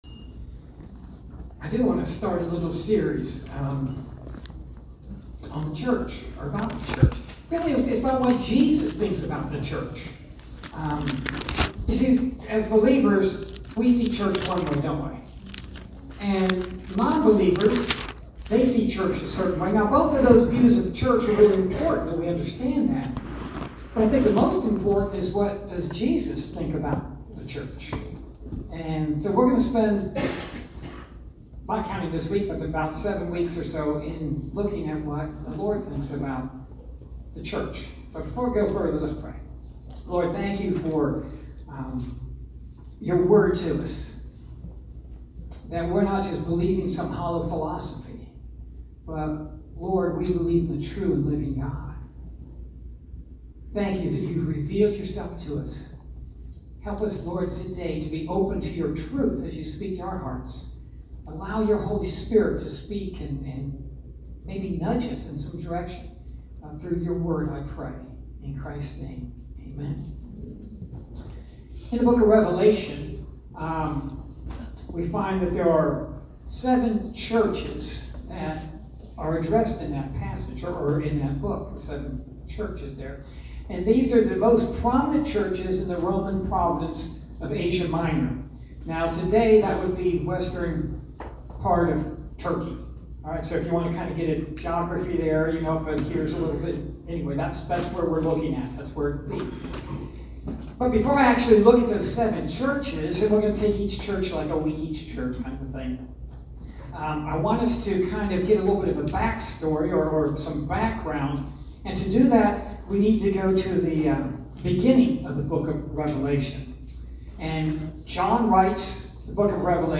Sermon--1-23.mp3